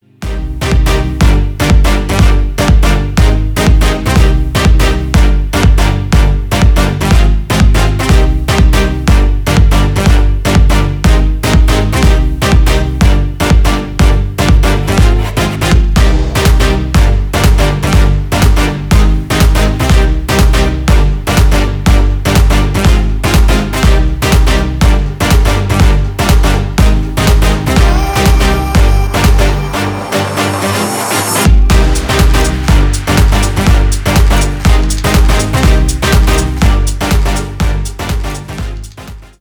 Танцевальные
без слов